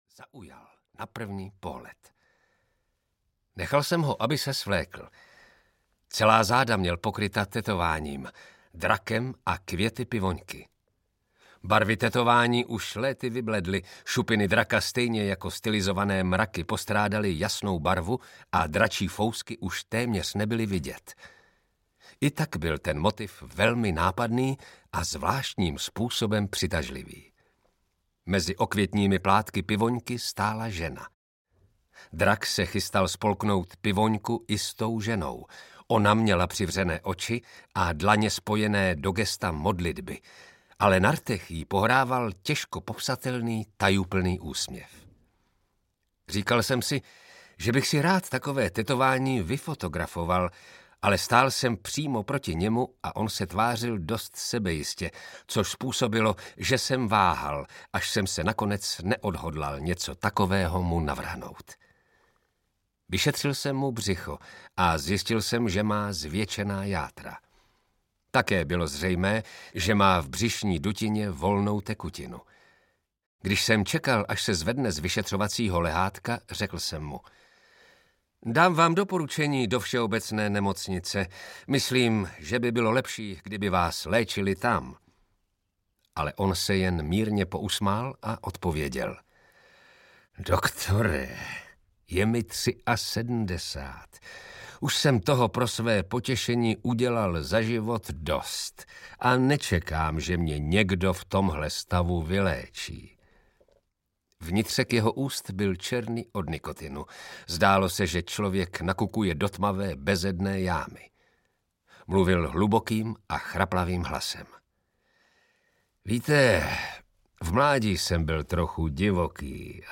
Zpověď šéfa jakuzy audiokniha
Ukázka z knihy
zpoved-sefa-jakuzy-audiokniha